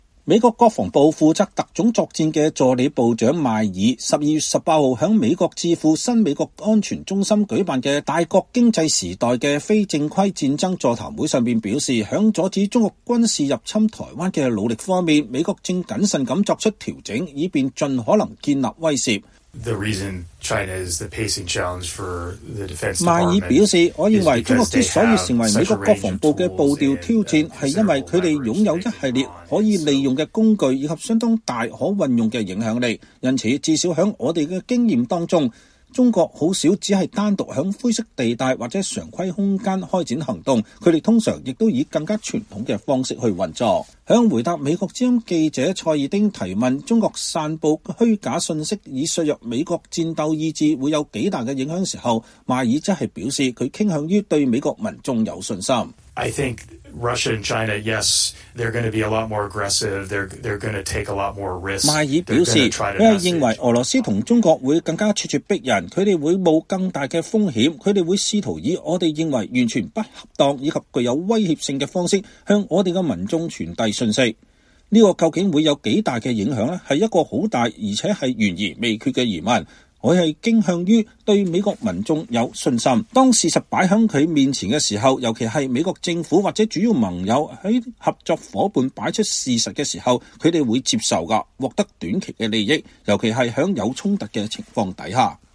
美國防部負責特種作戰的助理部長克里斯托弗·邁爾(Christopher Maier)12月18日在美國智庫新美國安全中心舉辦的“大國競爭時代的非正規戰爭”座談會上表示，在阻止中國軍事入侵台灣的努力面，美國正謹慎做出調整以便盡可能建立威懾。